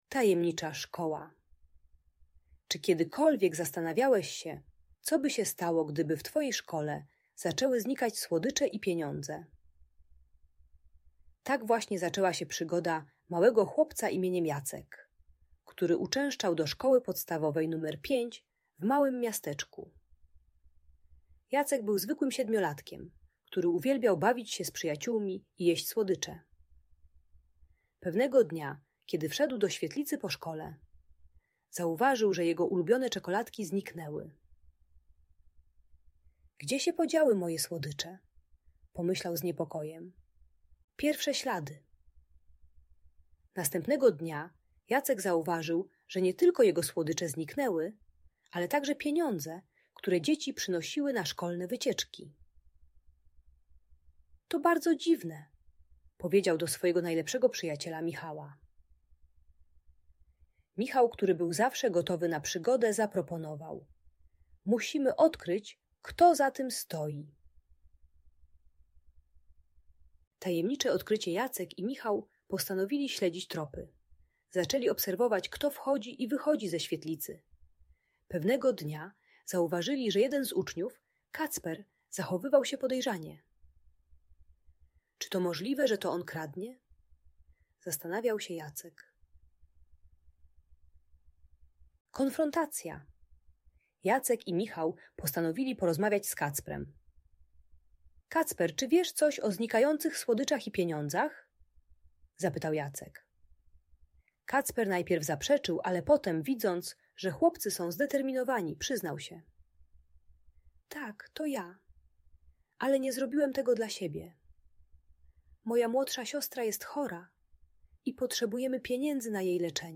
Tajemnicza Szkoła - Uczciwość i Zaufanie - Audiobajka dla dzieci